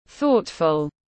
Rất chu đáo tiếng anh gọi là thoughtful, phiên âm tiếng anh đọc là /’θɔ:tfl/
Thoughtful /’θɔ:tfl/